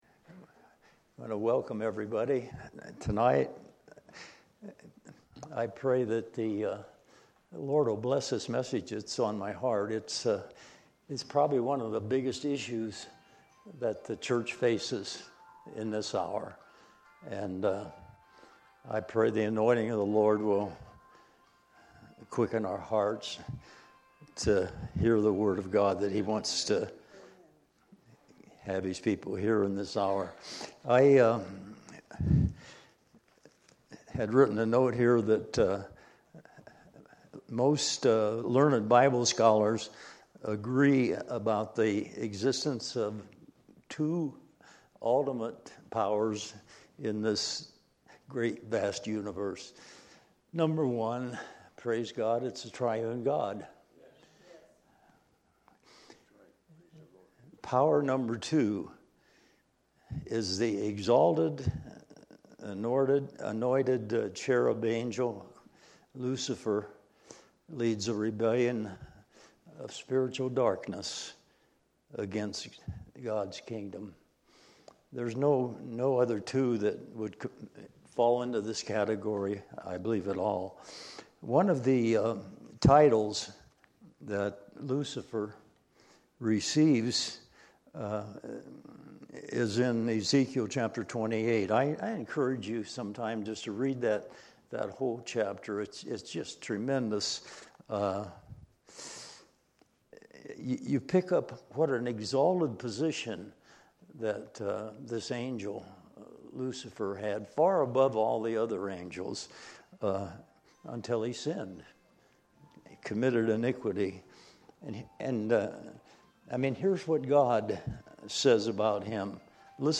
Testimonies, teachings, sharing.